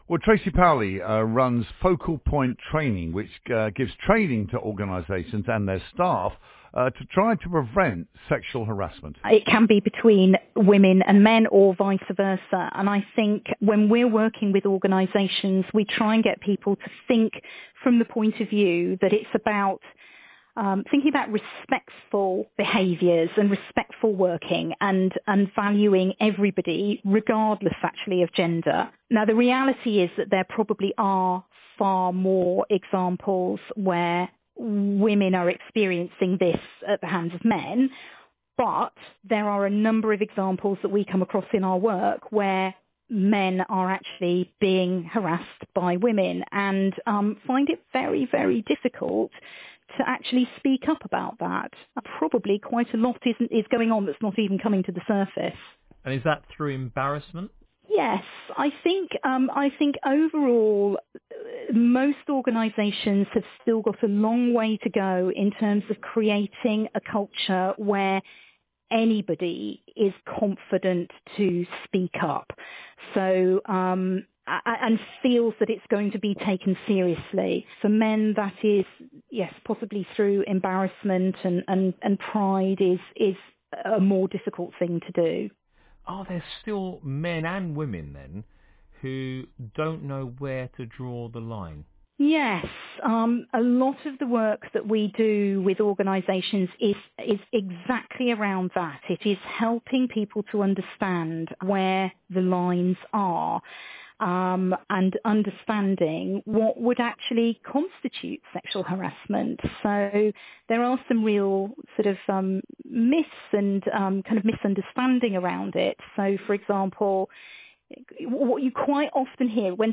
BBC Radio Kent’s breakfast show debate sparked by the policeman at the Notting Hill carnival arresting a woman who had slapped his backside.